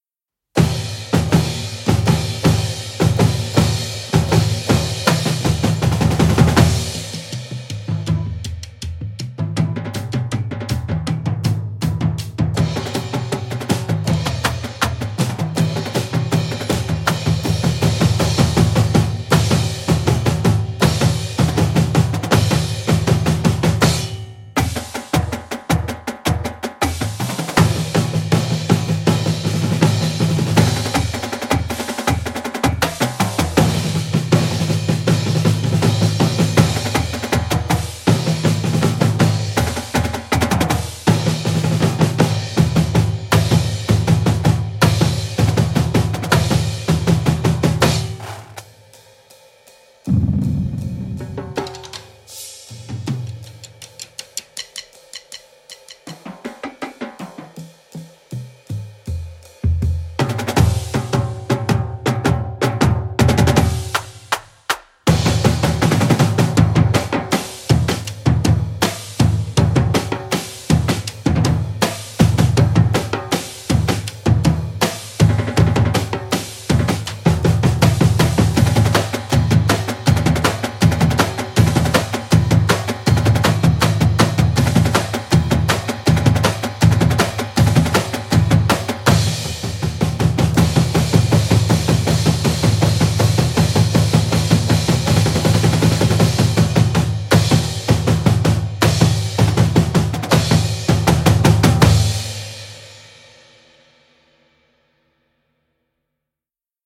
Voicing: 11-40 Percussion